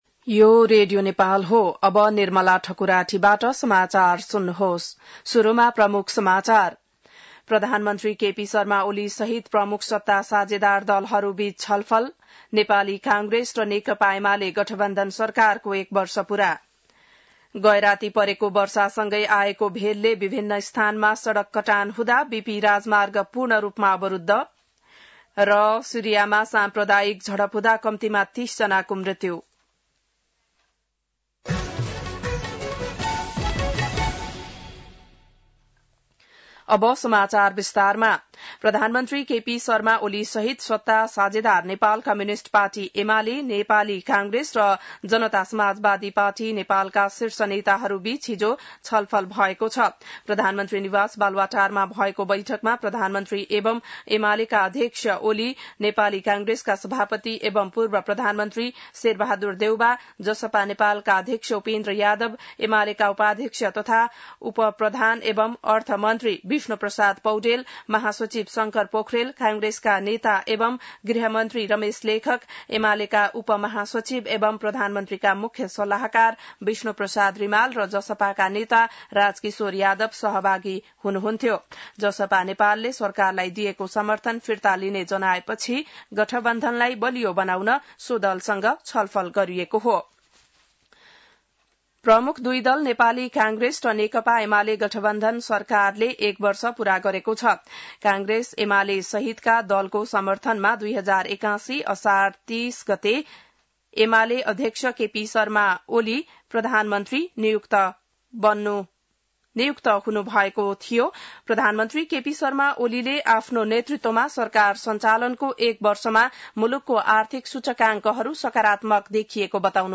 बिहान ९ बजेको नेपाली समाचार : ३१ असार , २०८२